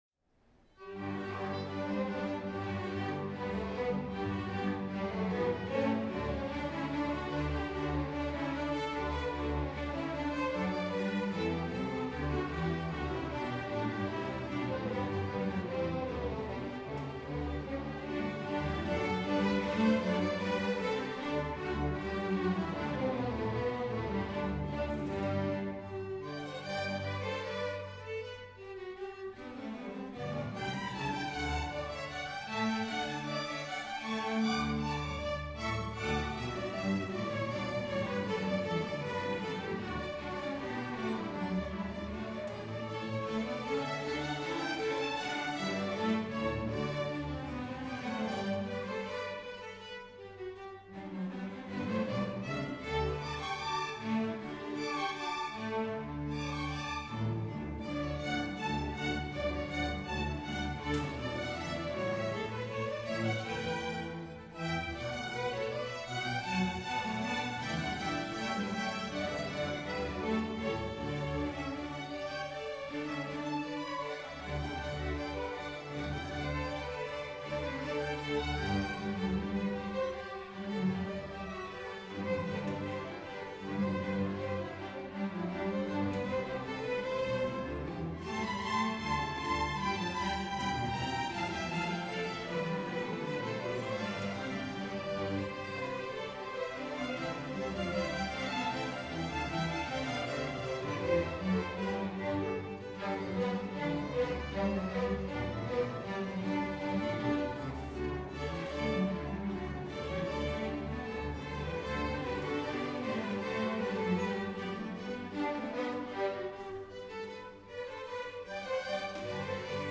At the final concert, when the conductor, after introducing the kids and the piece that they were about to play, announced that she would leave the stage and that the kids would play without a conductor as a demonstration of what they had learned about working as a team, we all held our breath and we listened, as a group of eleven year old kids played Brandenburg's Concerto as beautifully as it has ever been performed anywhere and -
they played as one.